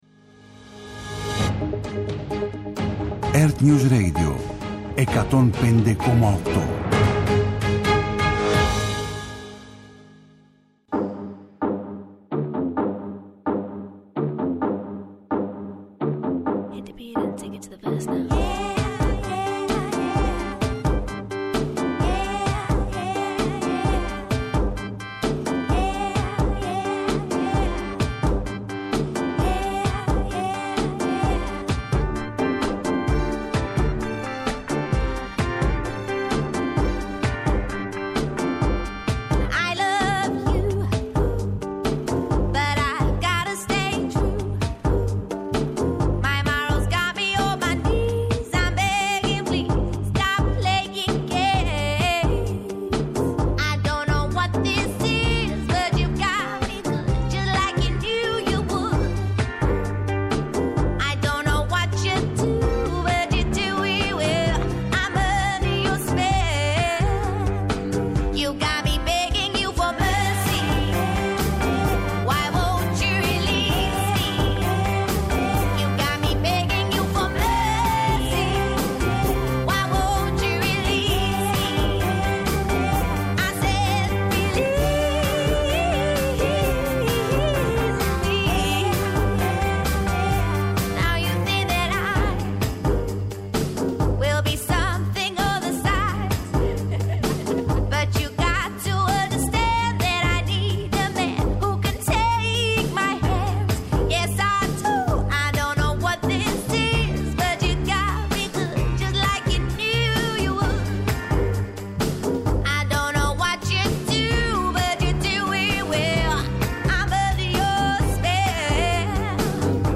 Μια εκπομπή συμπεριληπτική, με κοινωνικό πρόσημο και ετερόκλητο προσανατολισμό αναδεικνύει θέματα που απασχολούν την ελληνική και διεθνή επικαιρότητα και συνάμα παρακολουθεί τον διάλογο στη δημόσια ψηφιακή σφαίρα.